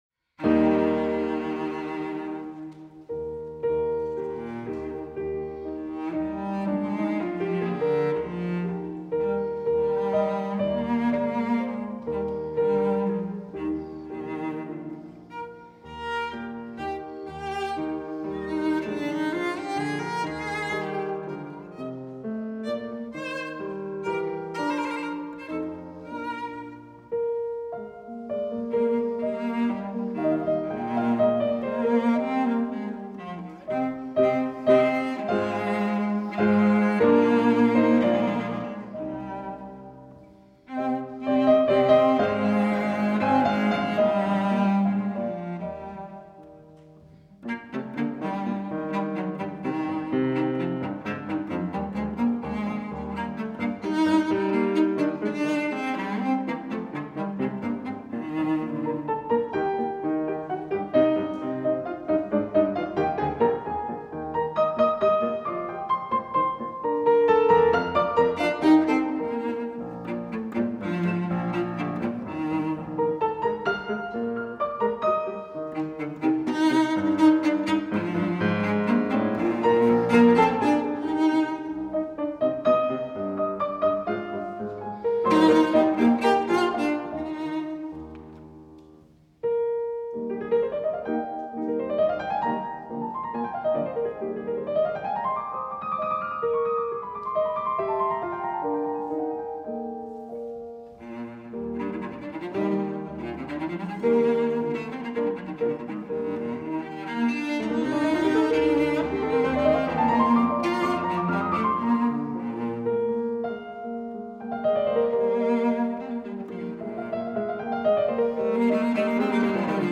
Classical (View more Classical Cello Music)